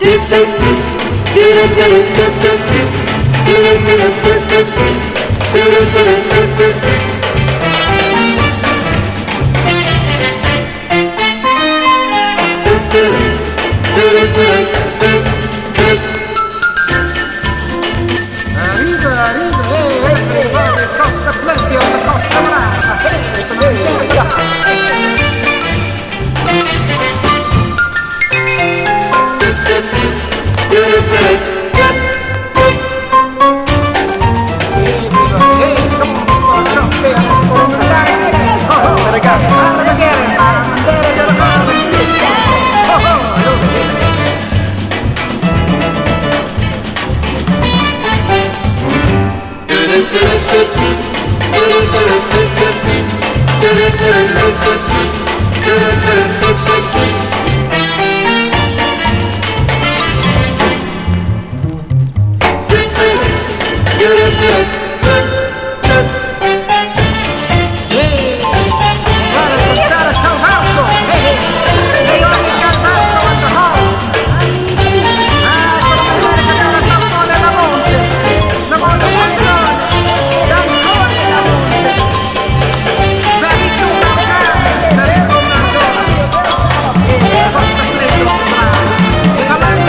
EASY LISTENING / EASY LISTENING / TV THEME / LIBRARY (UK)
TVテーマということでオープニングに適したトラックも多くライブラリー的にも使えます！